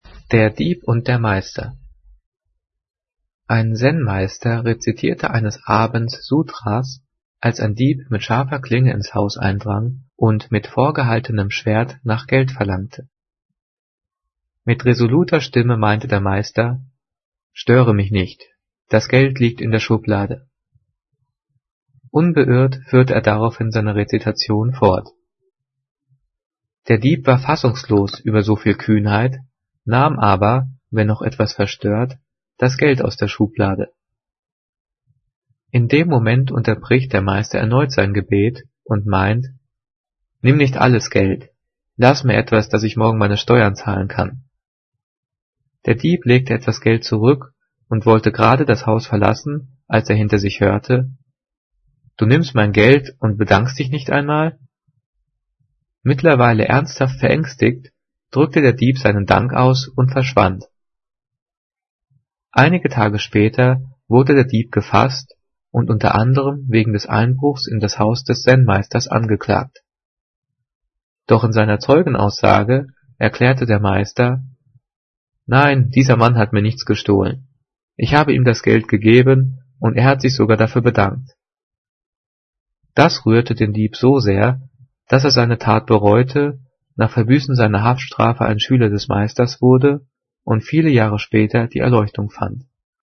Gelesen:
gelesen-der-dieb-und-der-meister.mp3